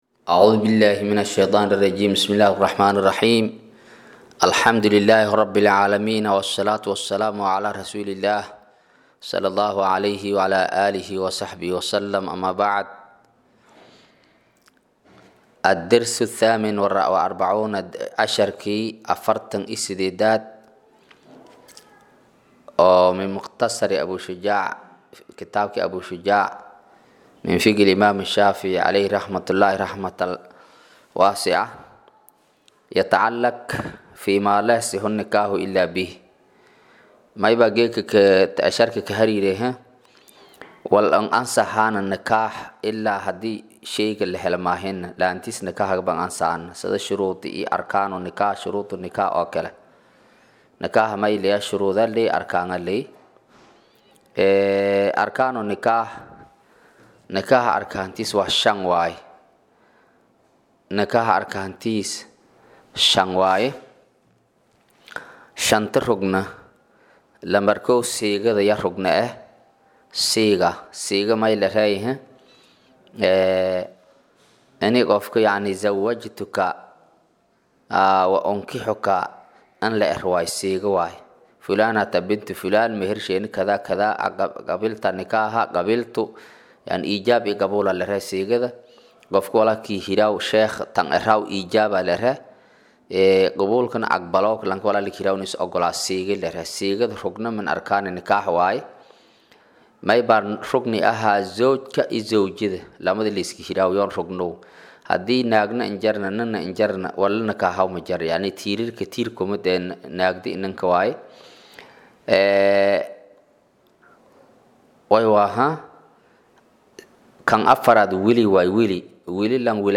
Maqal- Casharka Abuu Shujaac: Darsiga 48aad
Casharka-48_Kitaabka-Abuu-Shujaac.mp3